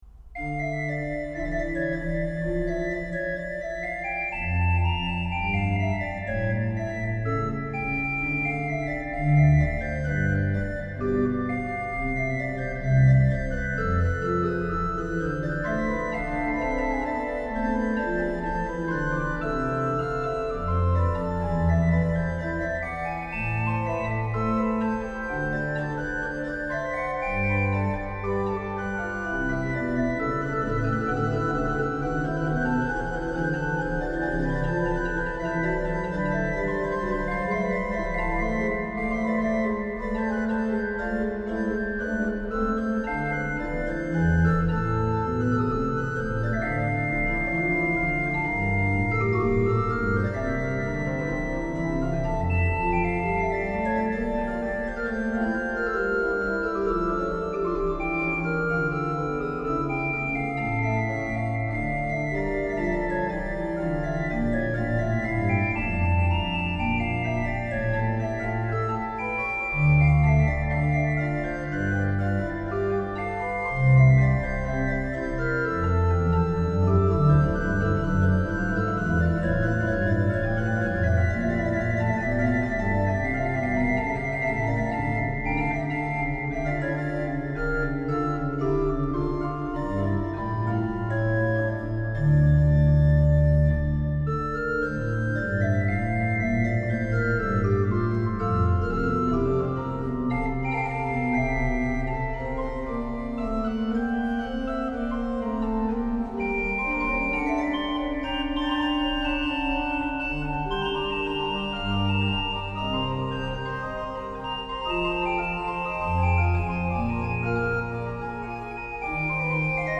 07 Sonata n°3 BWV 527 in D minor _ A